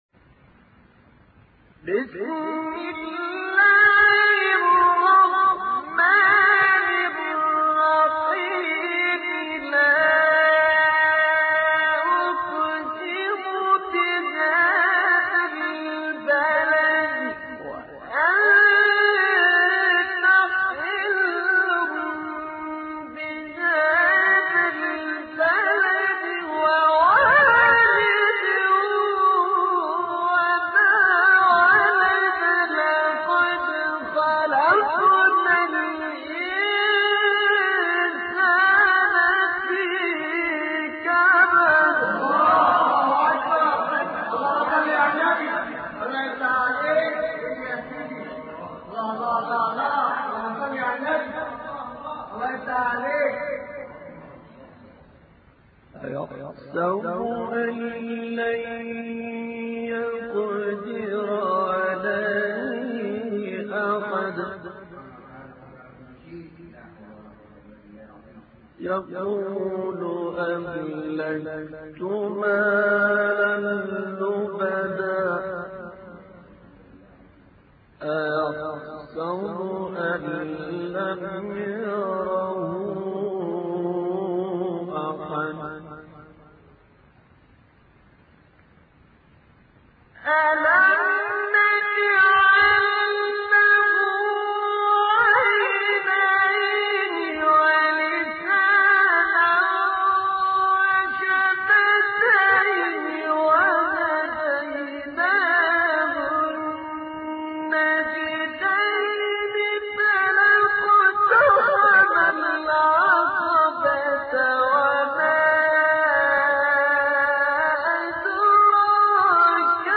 الكوثر _ المكتبة الصوتية للقرآن الكريم